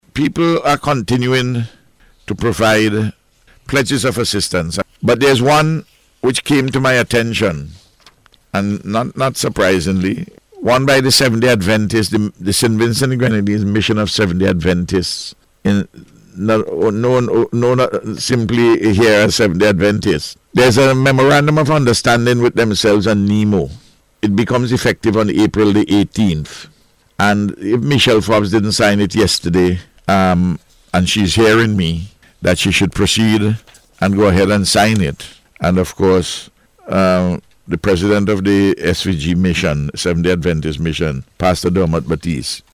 Speaking on NBC Radio on Thursday, the Prime Minister said the initiative will take effect on April 18th.